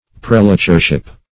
Search Result for " prelatureship" : The Collaborative International Dictionary of English v.0.48: Prelature \Prel"a*ture\ (?; 135), Prelatureship \Prel"a*ture*ship\, n. [F. pr['e]lature, or LL. praelatura.]